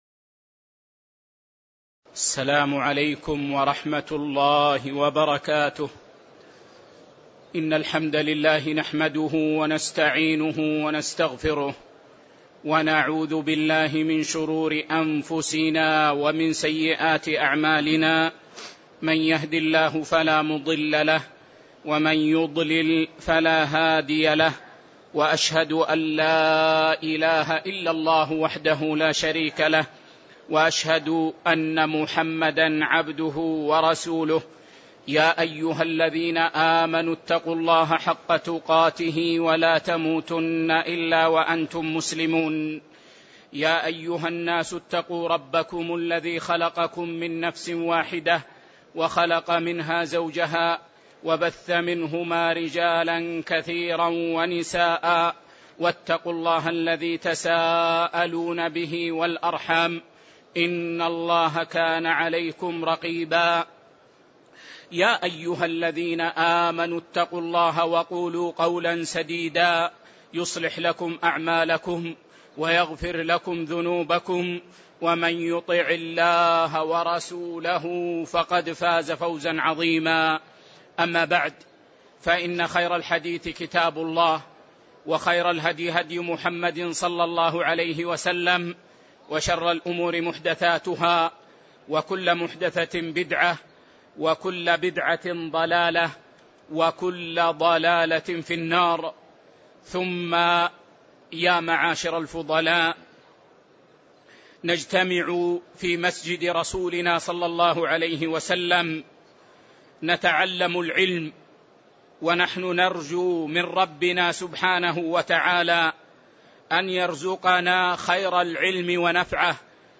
تاريخ النشر ٢٨ ذو القعدة ١٤٣٨ هـ المكان: المسجد النبوي الشيخ